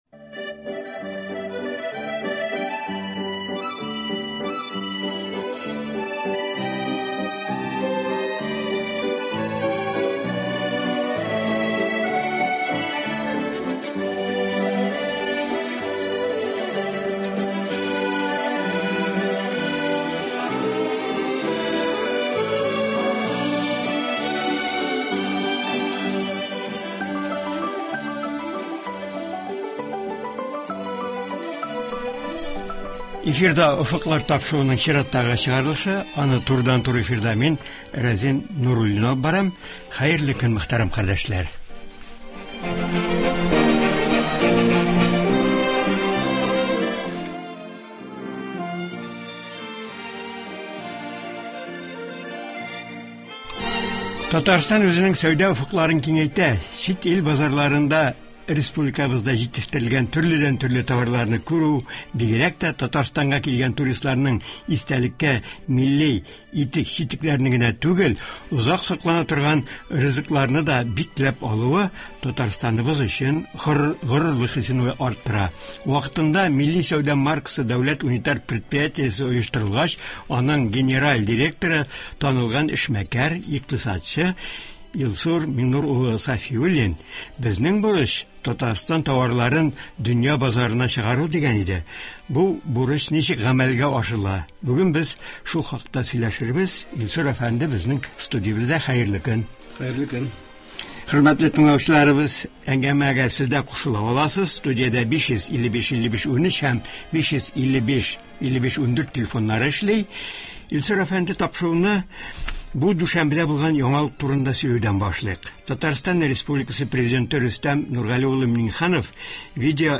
Болар хакында һәм башка яңалыклар турында турыдан-туры эфирда